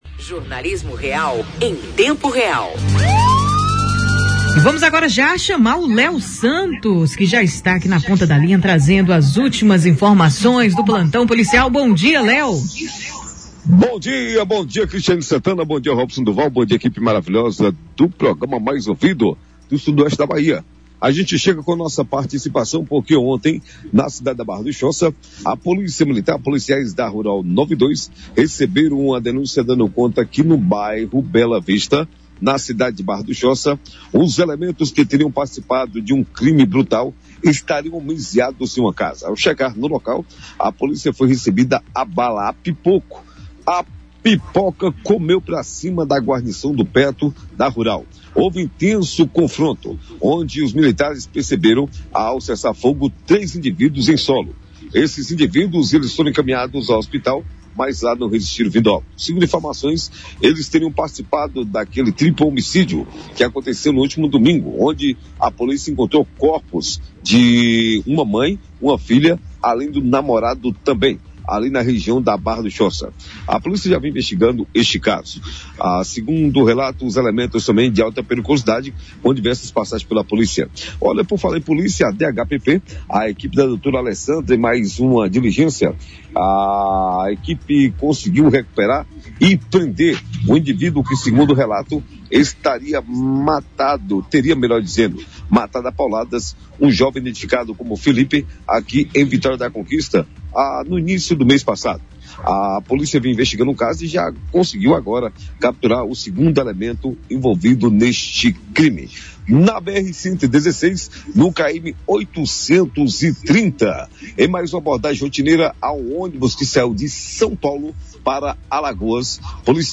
o repórter